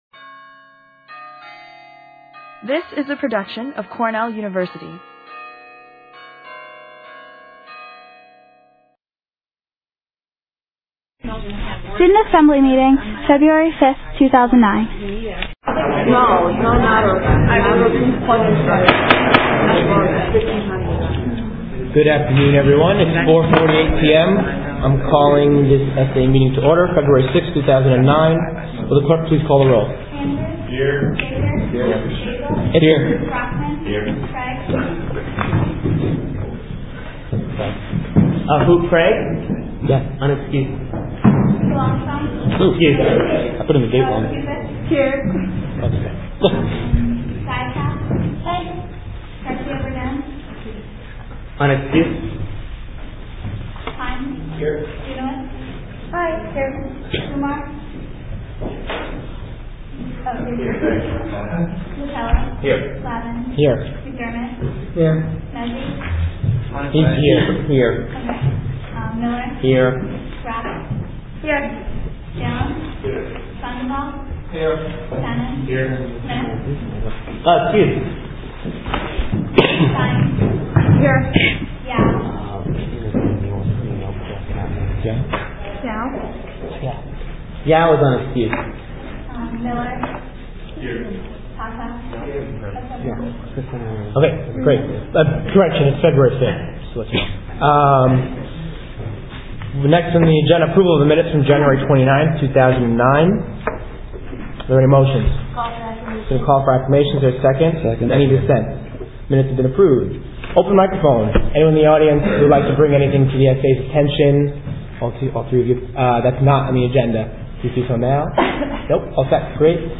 February 5, 2009 Meeting
Agenda Audio Note: Due to technical difficulties, only the first 30 minutes of the meeting recorded.